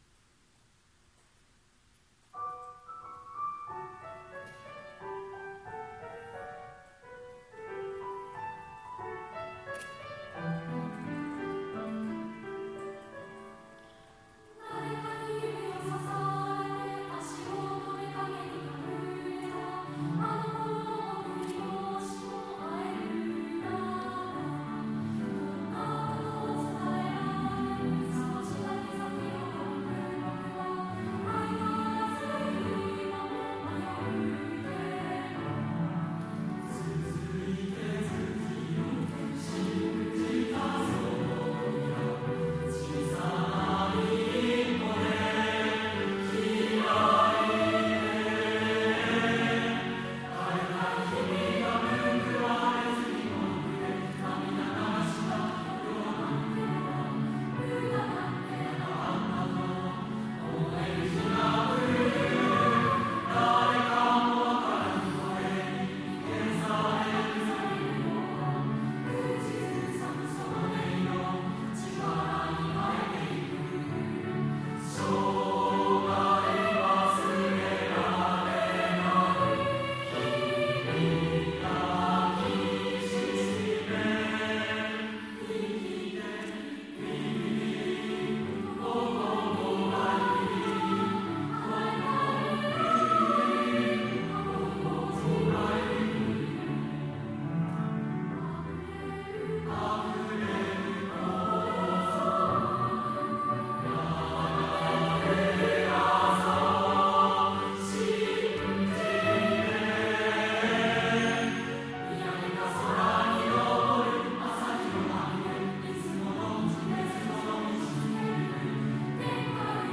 令和2年度合唱発表会の歌声＜3年学年合唱＞
令和2年10月24日（土）に開催しました文化発表会の合唱を掲載します。
3年学年合唱「足跡